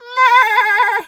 sheep_2_baa_high_02.wav